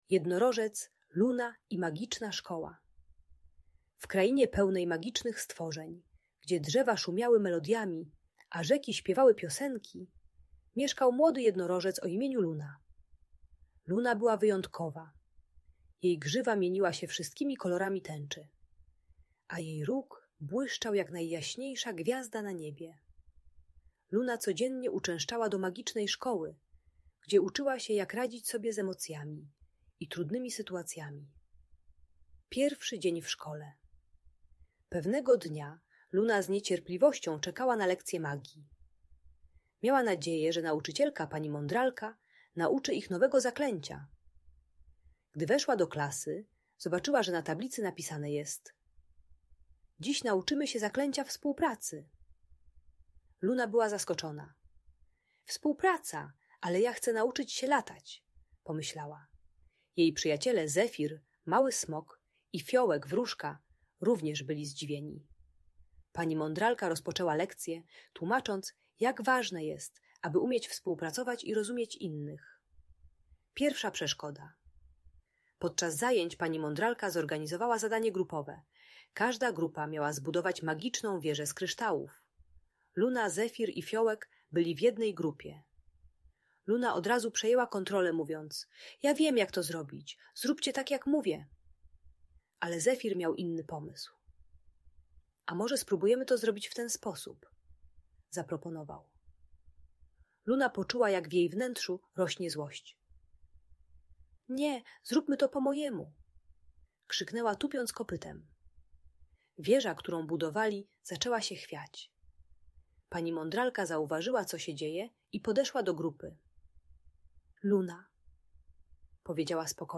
Jednorożec Luna uczy się, że kontrolowanie wszystkiego prowadzi do konfliktów. Audiobajka o współpracy i radzeniu sobie ze złością gdy inni mają inne pomysły.